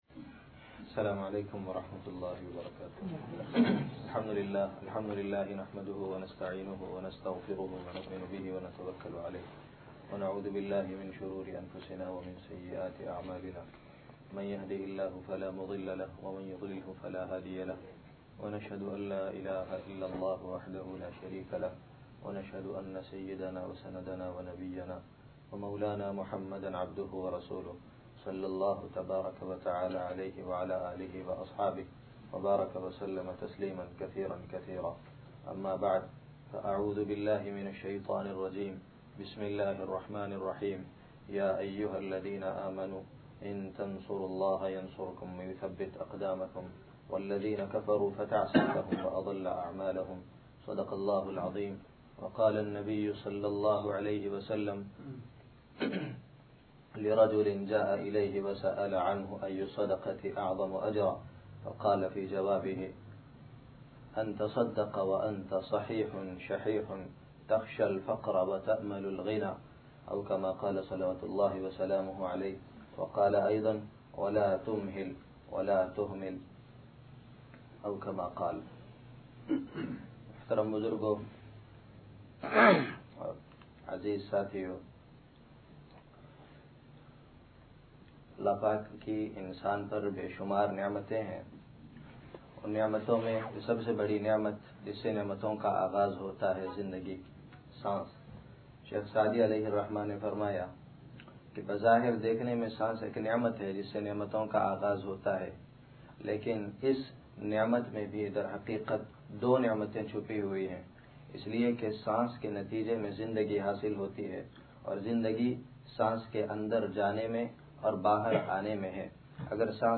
(Jum'ah Bayan)